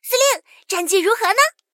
SU-76查看战绩语音.OGG